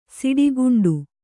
♪ siḍi guṇḍu